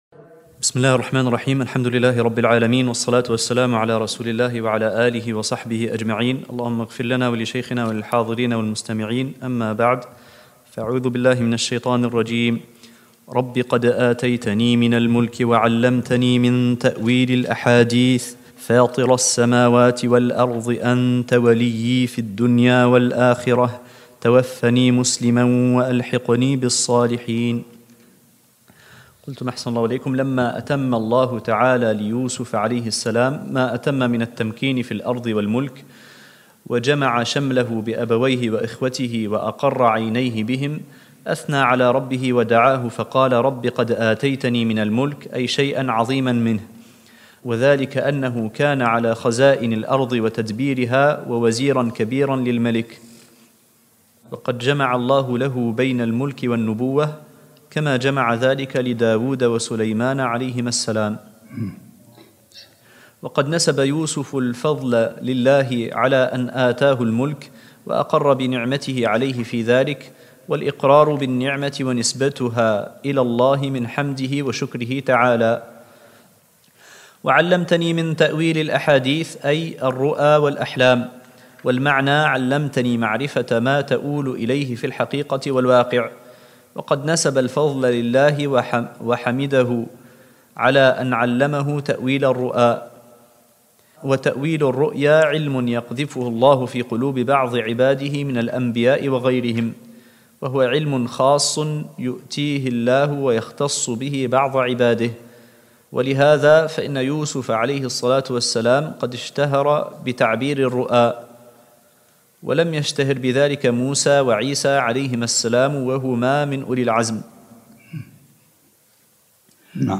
الدرس الثالث عشرمن سورة يوسف